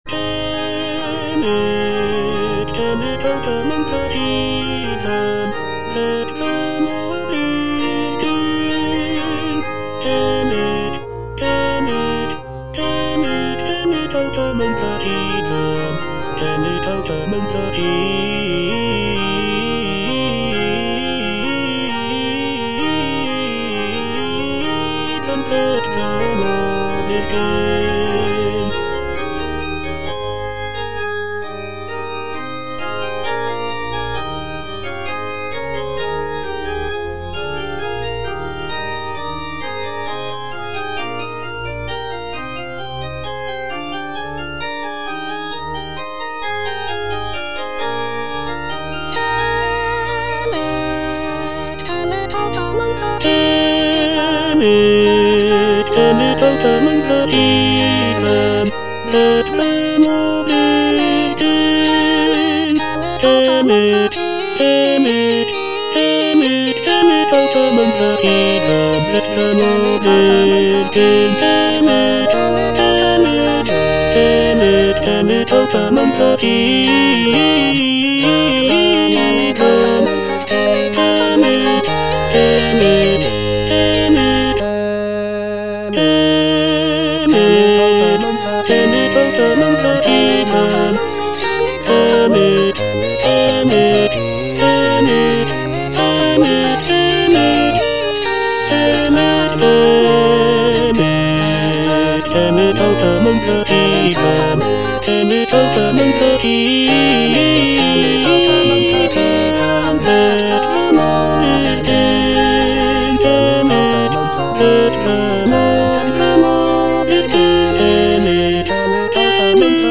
Choralplayer playing O come, let us sing unto the Lord - Chandos anthem no. 8 HWV253 (A = 415 Hz) by G.F. Händel based on the edition CPDL #09622
G.F. HÄNDEL - O COME, LET US SING UNTO THE LORD - CHANDOS ANTHEM NO.8 HWV253 (A = 415 Hz) Tell it out among the heathen - Tenor (Emphasised voice and other voices) Ads stop: auto-stop
It is a joyful and celebratory piece, with uplifting melodies and intricate harmonies.
The use of a lower tuning of A=415 Hz gives the music a warmer and more resonant sound compared to the standard tuning of A=440 Hz.